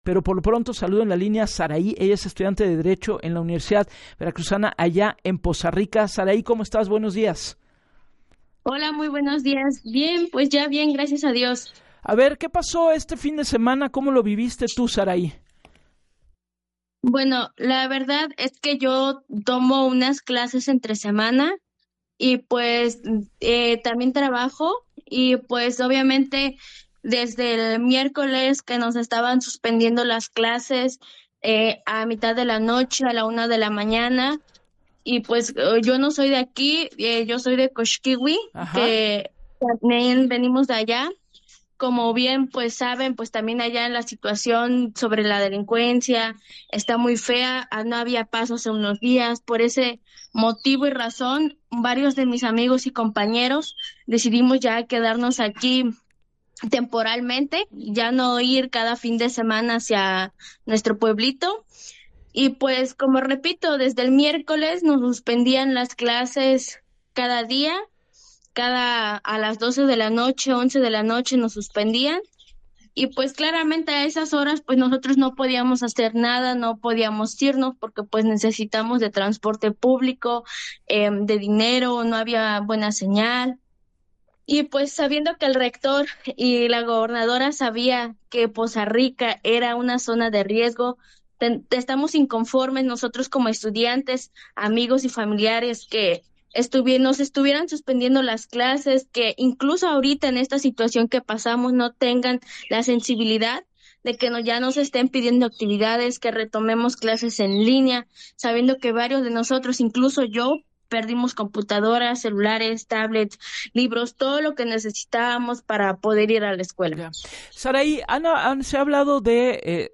En entrevista con Gabriela Warkentin para el programa “Así las Cosas”